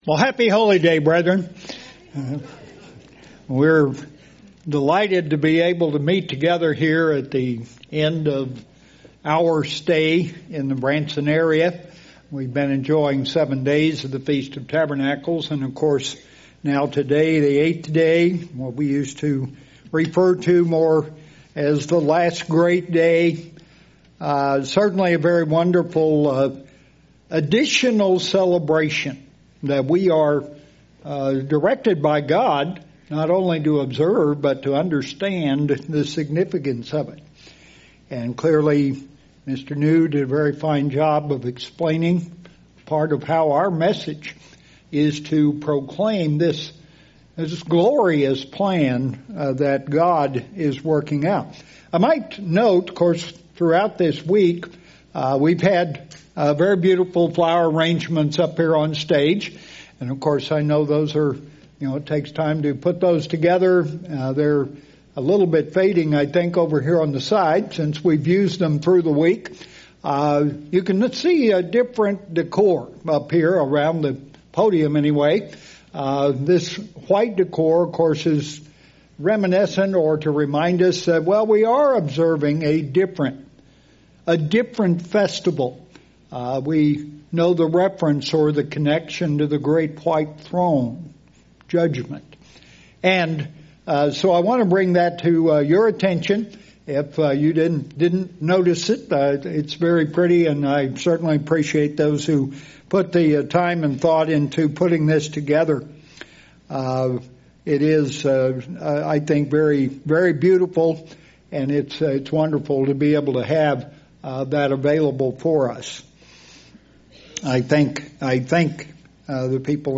In this sermon I want to emphasize the fairness and mercy of God and His desire to see all mankind repent and choose to obey and serve Him.